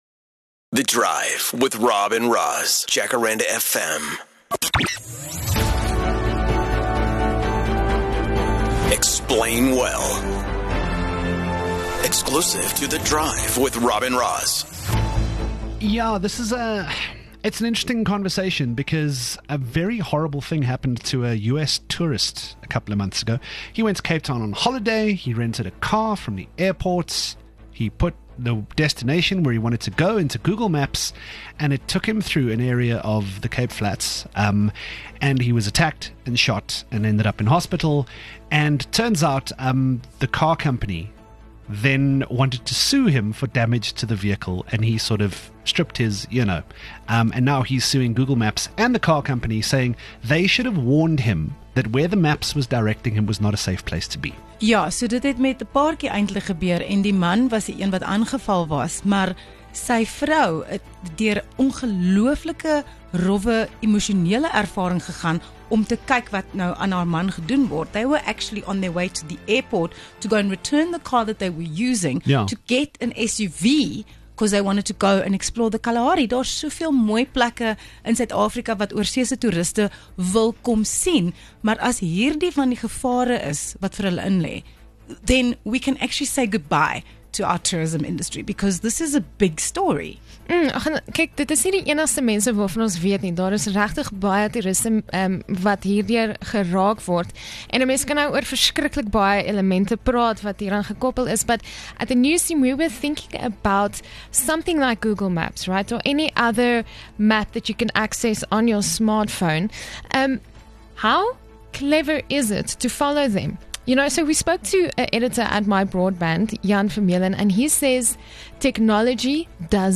There are many harrowing stories of drivers following routes provided by map applications on their phones only to end up in dire situations. Take a listen as the team discusses these maps and how to avoid a dangerous situation.